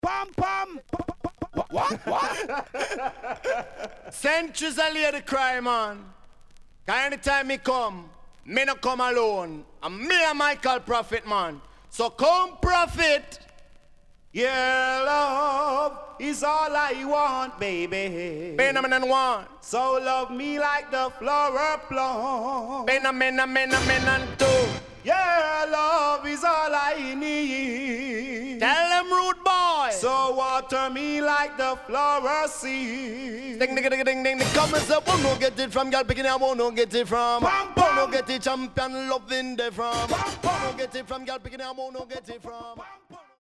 HOME > Back Order [DANCEHALL DISCO45]  >  COMBINATION
SIDE A:少しチリノイズ入りますが良好です。